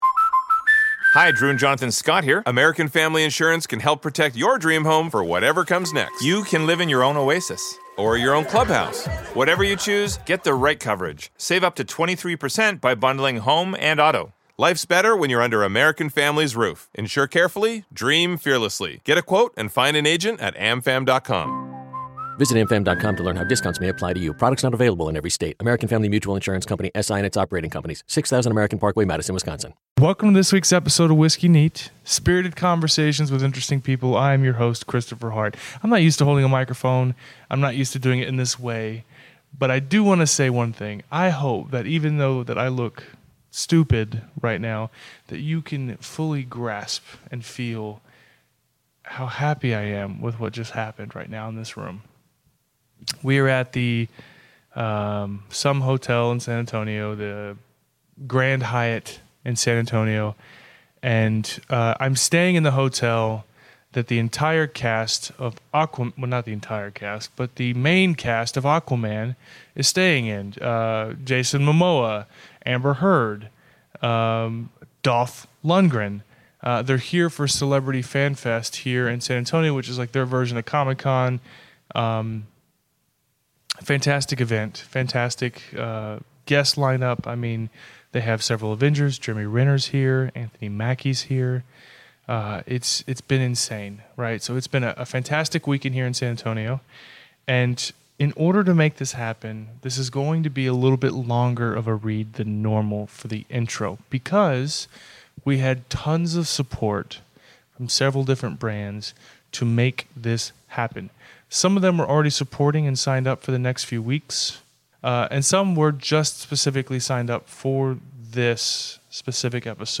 This week I sit down with Film star and Hollywood Legend, Dolph Lundgren.